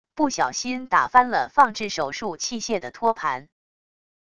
不小心打翻了放置手术器械的托盘wav音频